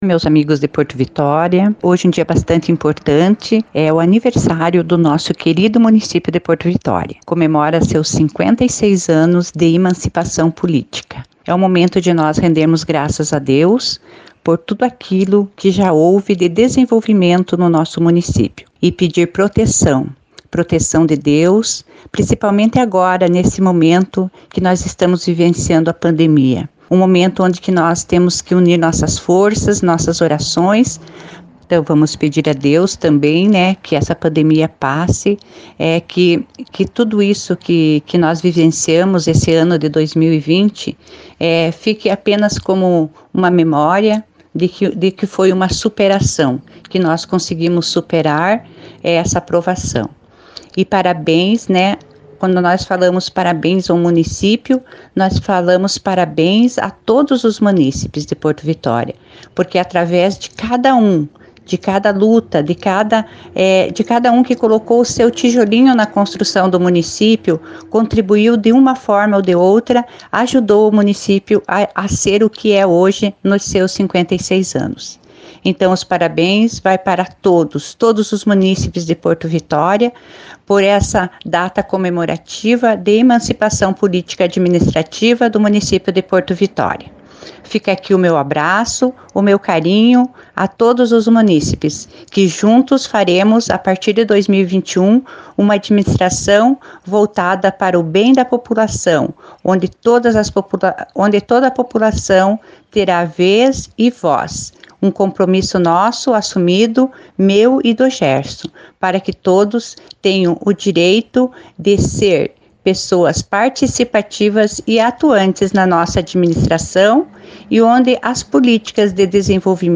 Na oportunidade a prefeita eleita de Porto Vitória, Marisa Ilkiu, também parabenizou a cidade pelo aniversário.